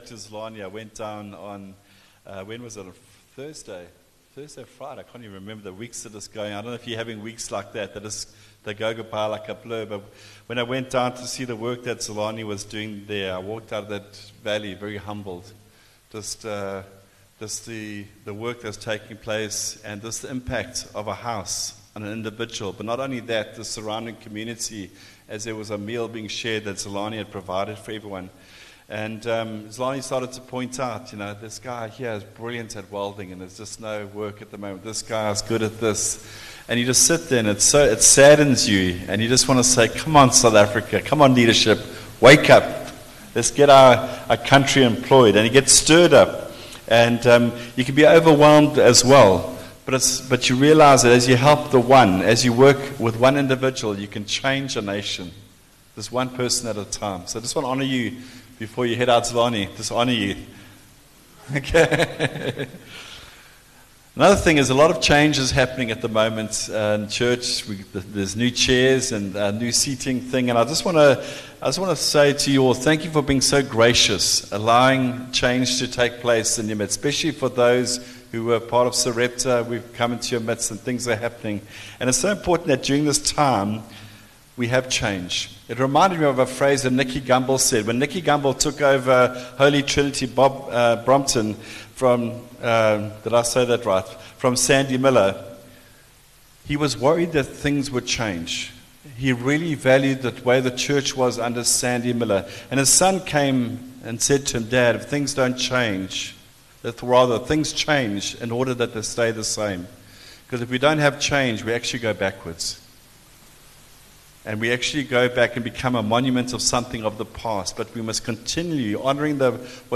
JacPod View Promo Continue JacPod Install Upper Highway Vineyard Sunday messages 15 Sep Preach - 15 Sept 2024 26 MIN Download (12.3 MB) ENGLISH SOUTH AFRICA 00:00 Playback speed Skip backwards 15 seconds